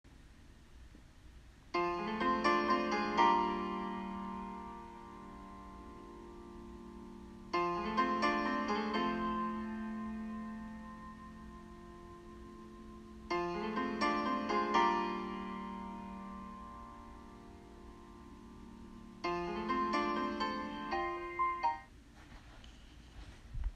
Somehow, I got the opening piano notes to a song stuck in my head last week, and I just could not think of what song they went with: